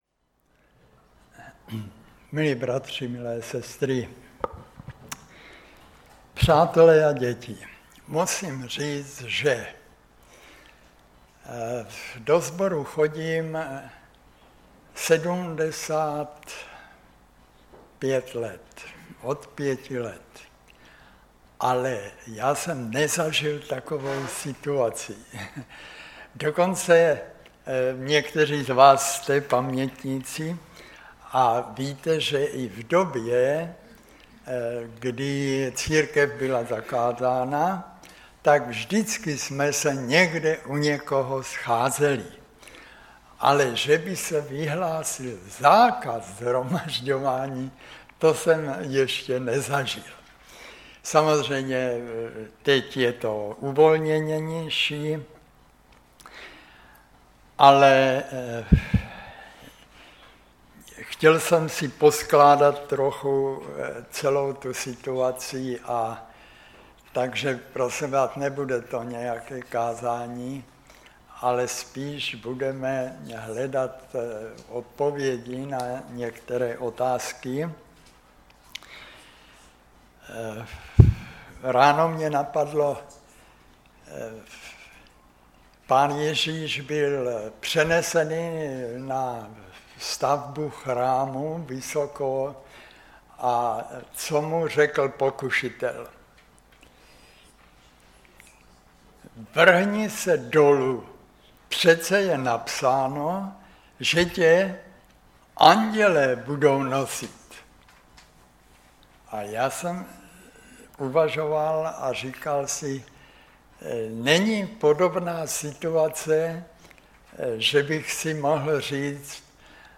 Kázání
ve sboře Ostrava-Radvanice